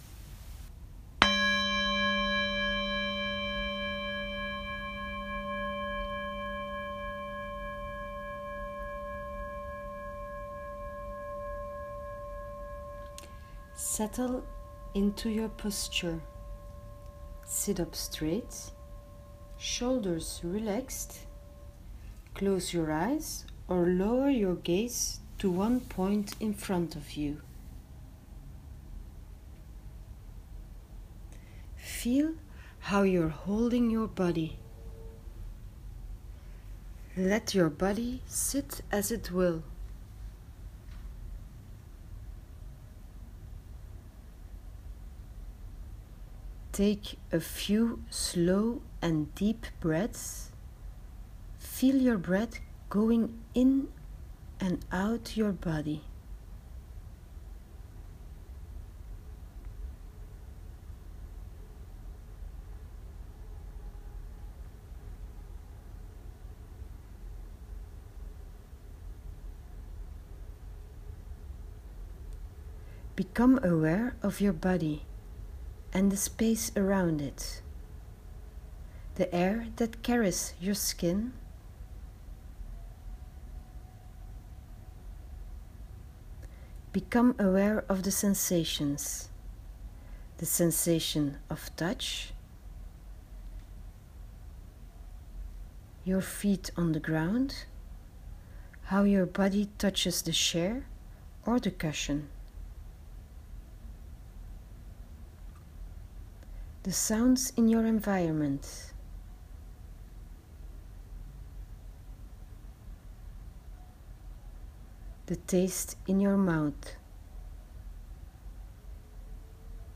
You’ll find the guided meditation as a voice recording under the screen with the introduction video.